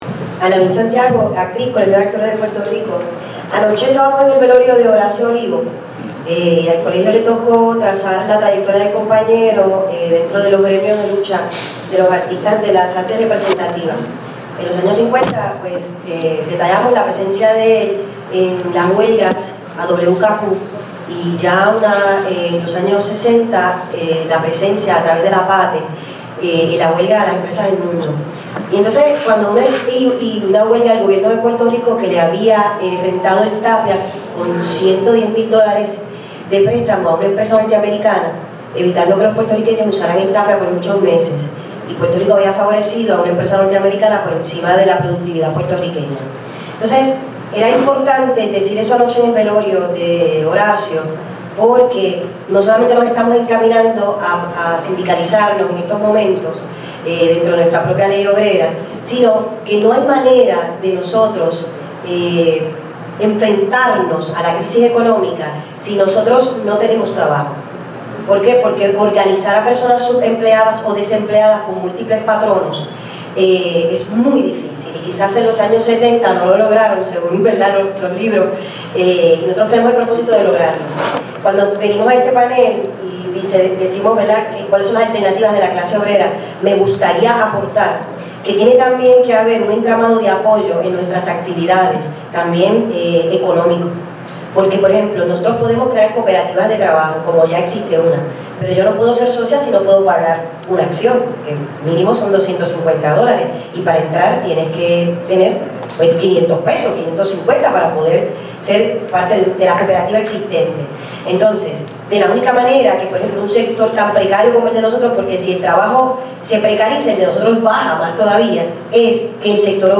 El foro tuvó lugar en el Local de la UTIER en la calle Cerra 612 en Santurce.
Comentarios y discusión del público